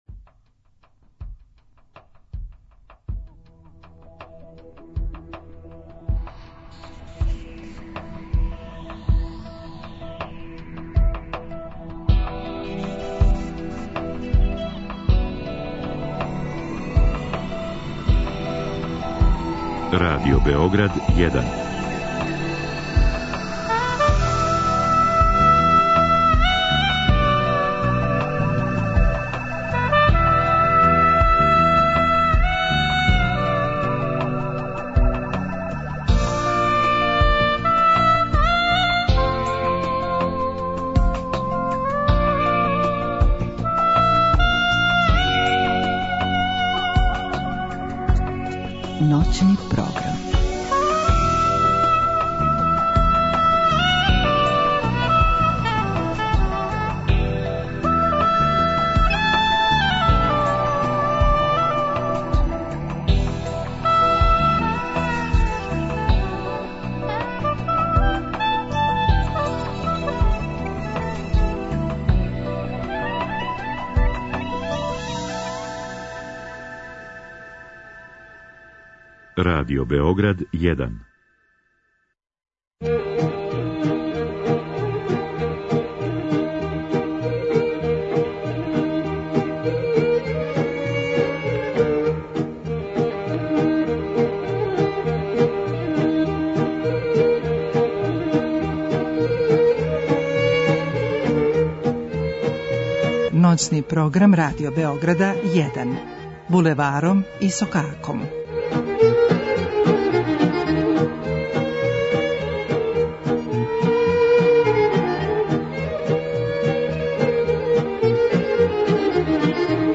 Слушаћемо и старе народне пеме и кола из Босне и Македоније.